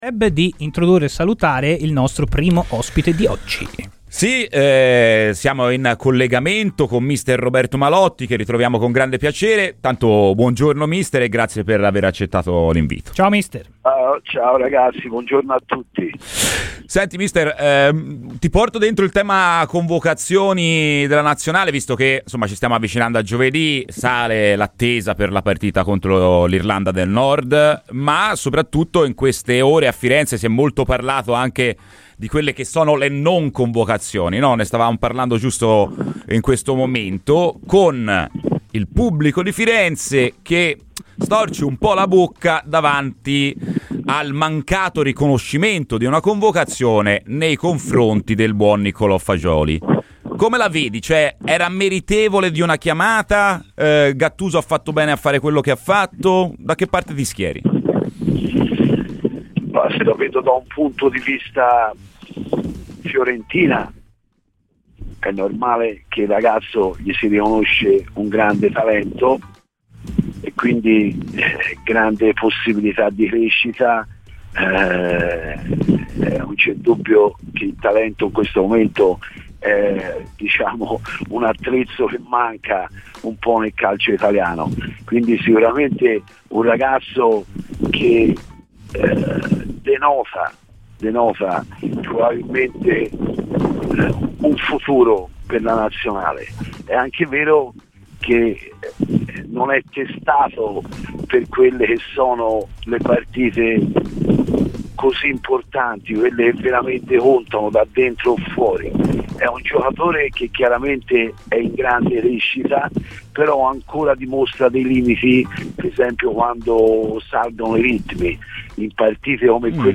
Non vedo tutte queste grandi partite fatte o tanto meno un gioco spettacolare".ù Ascolta il podcast per l'intervista completa.